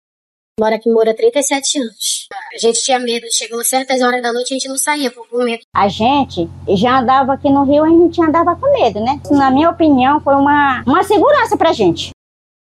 Sonora-moradoras.mp3